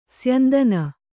♪ syandana